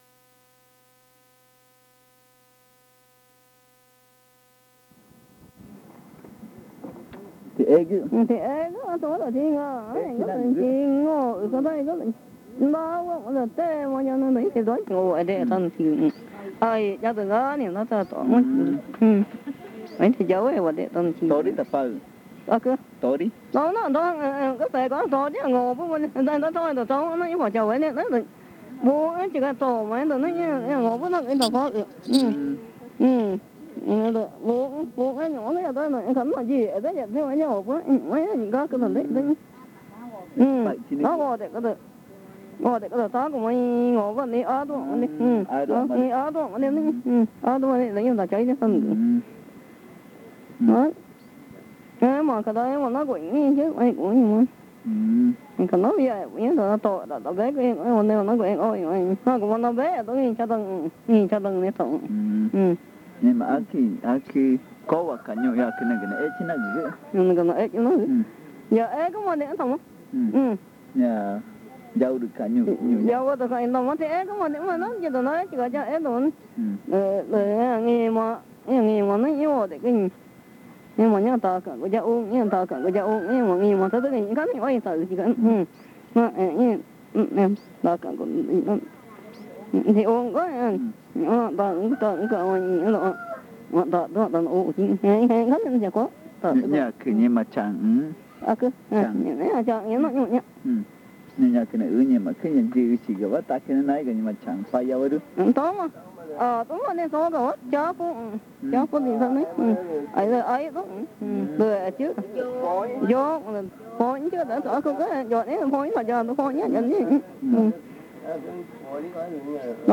Encuesta léxica y gramatical 13. Pupuña n°2
Este casete es el segundo de una serie de cuatro casetes grabados en Pupuña, de los cuales ponemos a disposición solo tres, dado que el cuarto no pudo ser digitalizado por problemas del casete mismo.
El audio contiene solo el lado B, al encontrase el lado A vacío.